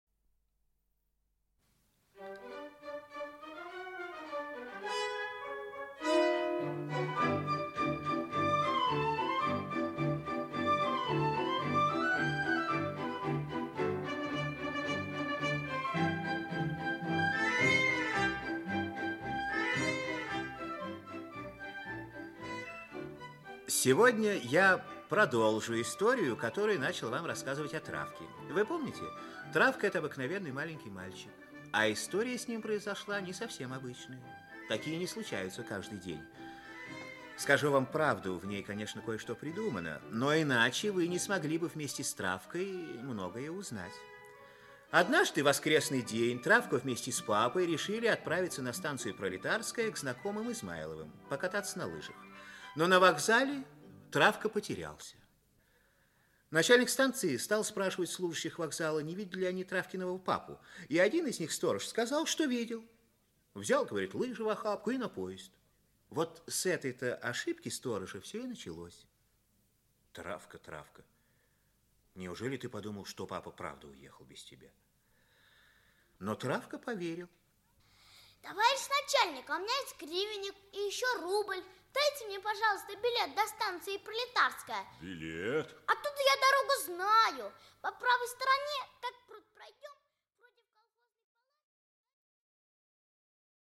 Аудиокнига Приключения Травки. Часть 2 | Библиотека аудиокниг
Часть 2 Автор Сергей Григорьевич Розанов Читает аудиокнигу Актерский коллектив.